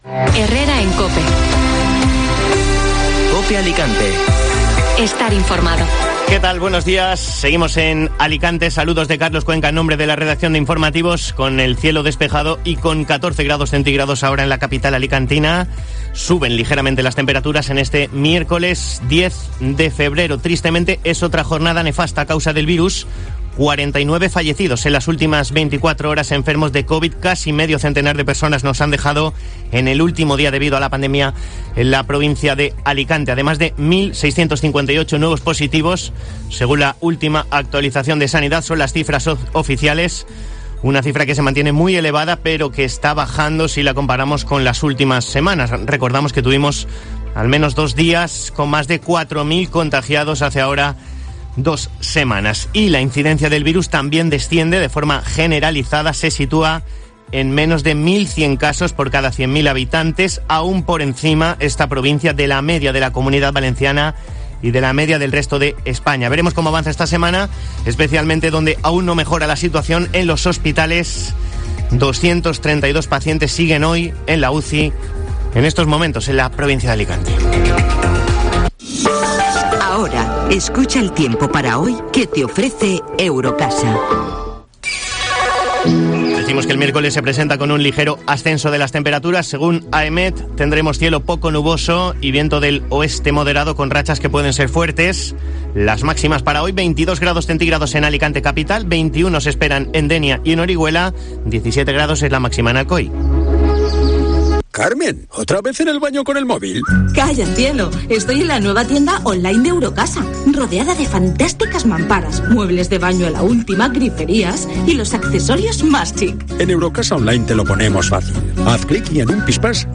Informativo matinal Alicante (Miércoles 10 de febrero)